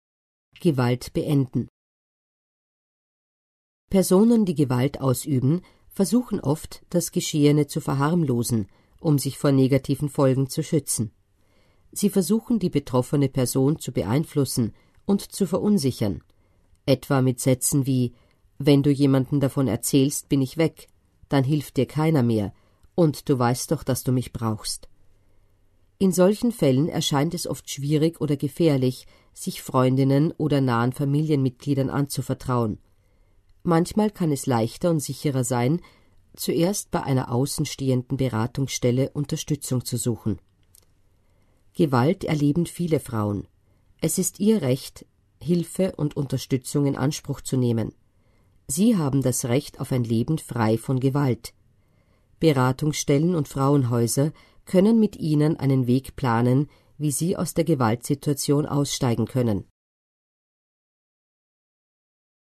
Hier finden Sie die österreichische Broschüre für Frauen mit Behinderungen als Audioversion: „Gewalt, was kann ich tun? Informationen für Frauen mit Behinderungen.“